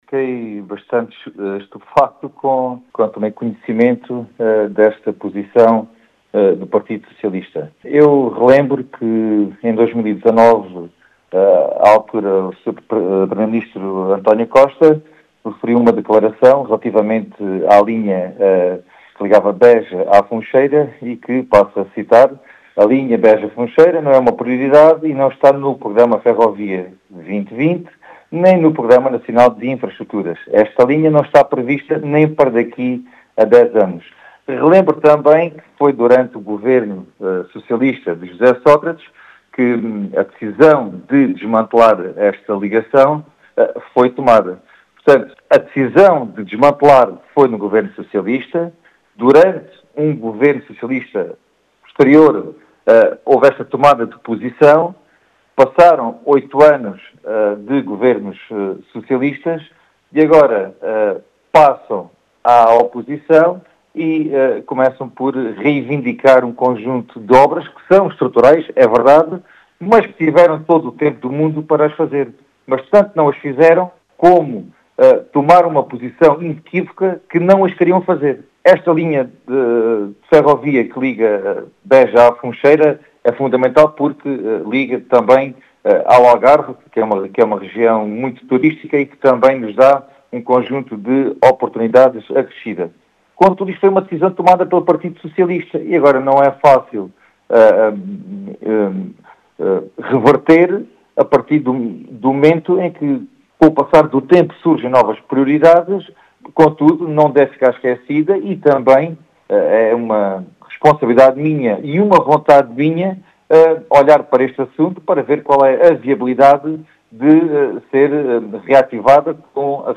Em declarações à Rádio Vidigueira, Gonçalo Valente diz ter ficado “estupefacto” com a posição da Federação do Baixo Alentejo do PS.